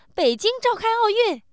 surprise